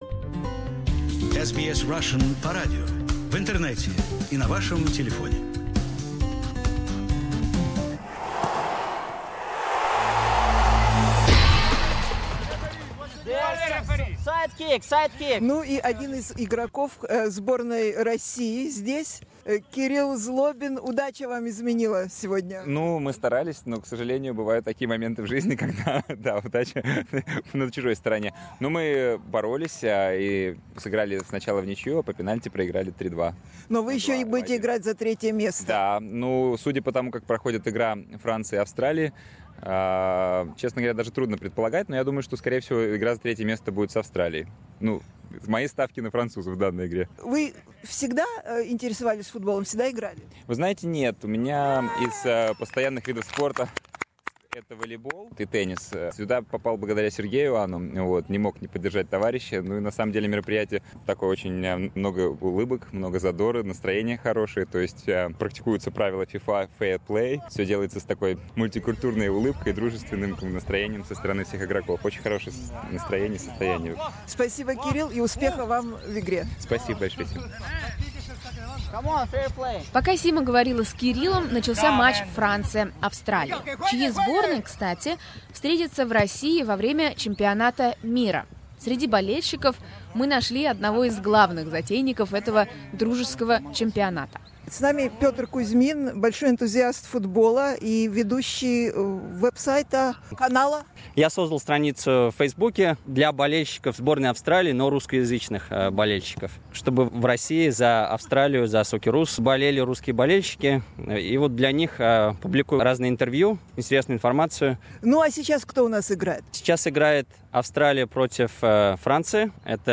We speak with the referee, organisers and the players of the community football friendlies.